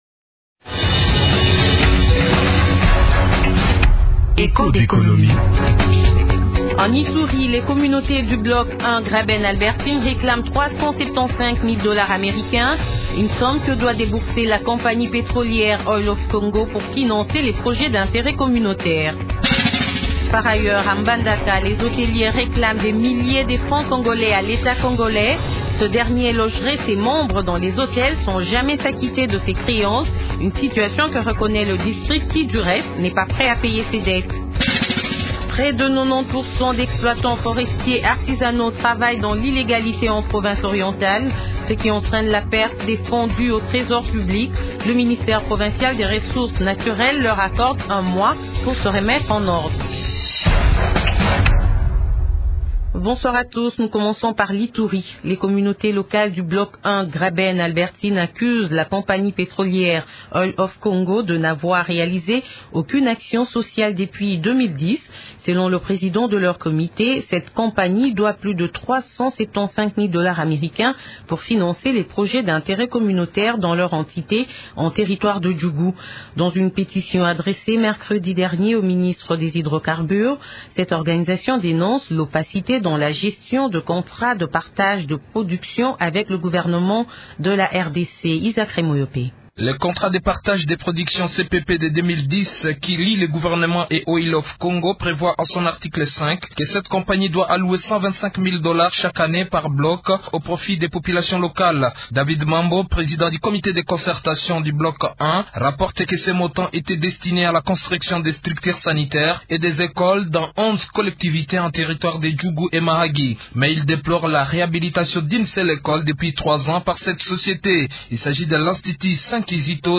Ecoutez l’émission présentée par